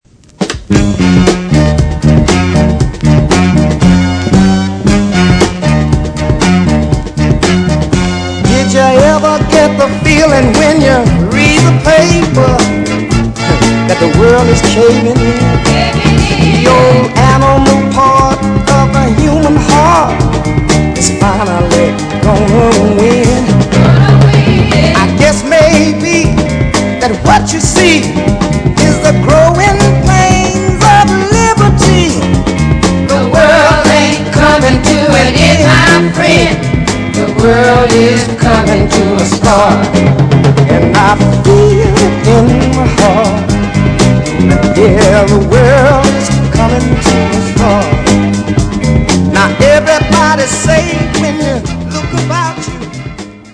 Traditional 60's Soul dancer - great vocals!